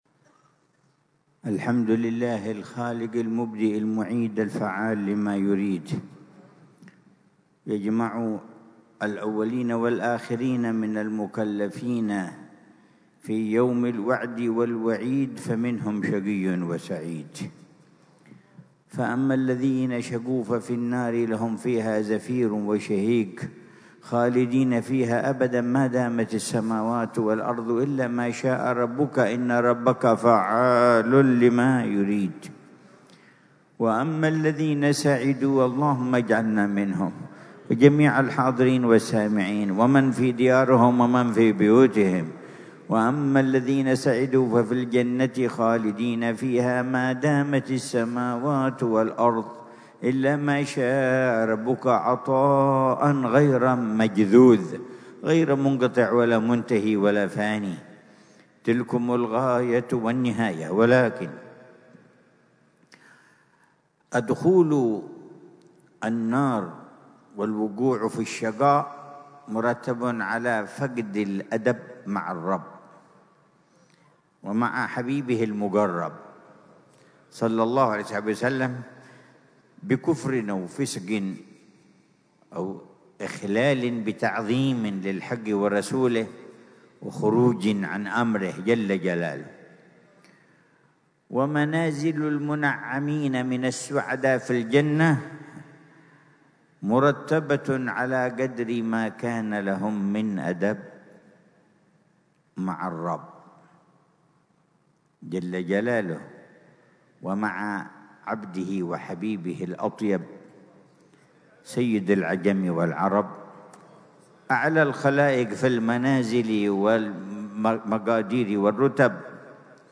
محاضرة العلامة الحبيب عمر بن محمد بن حفيظ ضمن سلسلة إرشادات السلوك، ليلة الجمعة 11 ذو القعدة 1446هـ في دار المصطفى بتريم، بعنوان: